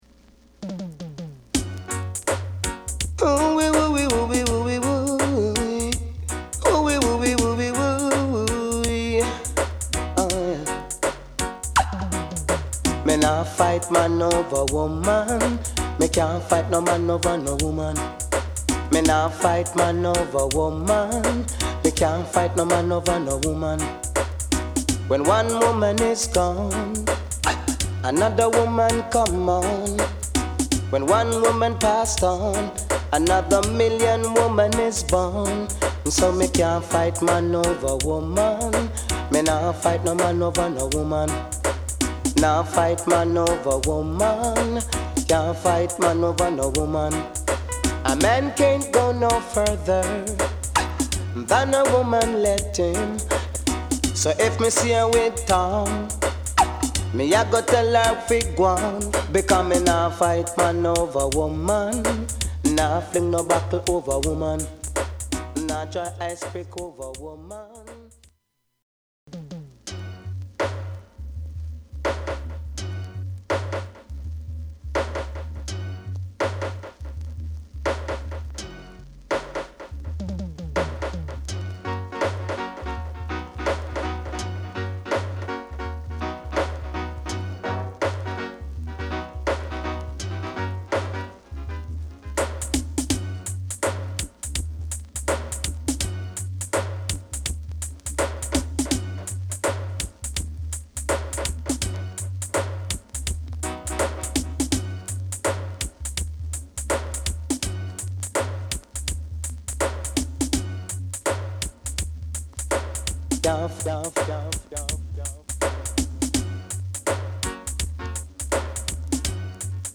REGGAE / DANCEHALL
プレス・ノイズ有り（JA盤、Reggaeのプロダクション特性とご理解お願い致します）。